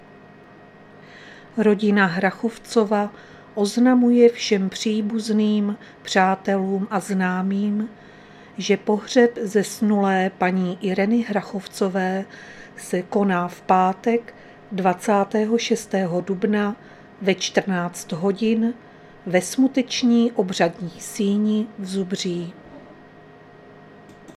Záznam hlášení místního rozhlasu 24.4.2024